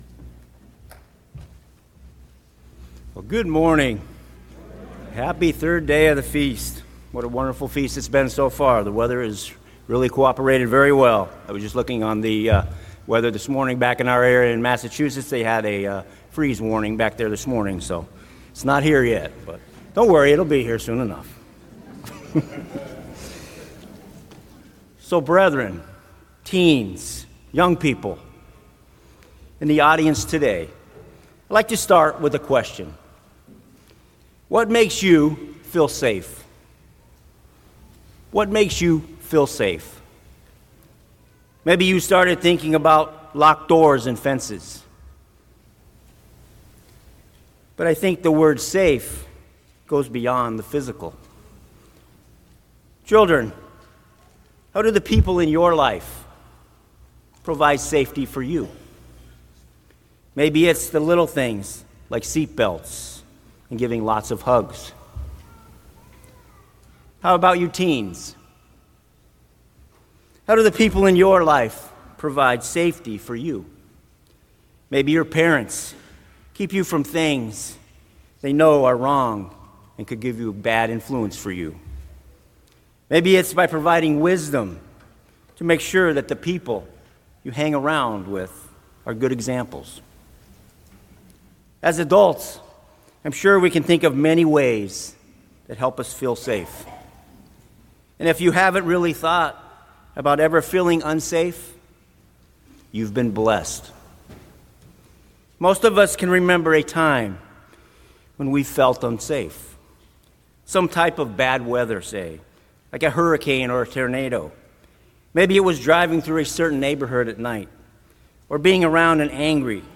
Sermons
Given in Lake Geneva, Wisconsin